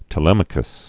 (tə-lĕmə-kəs)